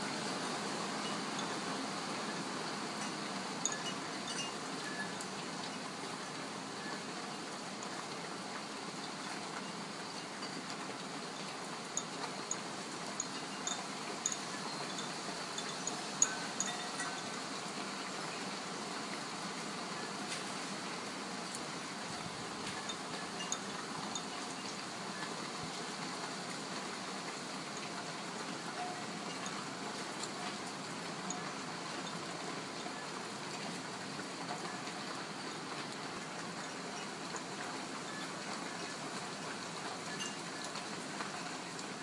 雨 " 雨
2011年6月傍晚时分的雨。2支森海塞尔MKH60话筒，一个舒尔FP24前级放大器输入奥林巴斯LS10录音机。
标签： 现场录音
声道立体声